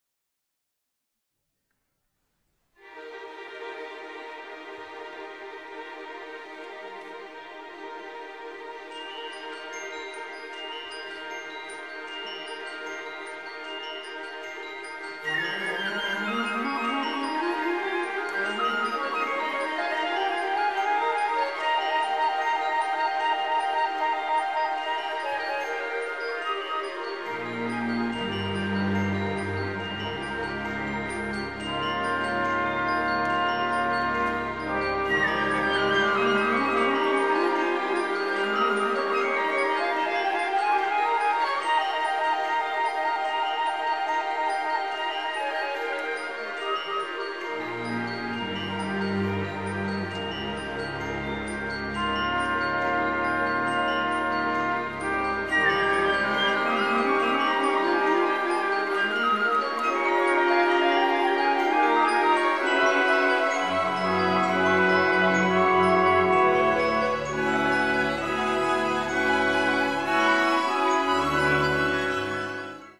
pièce pour orchestre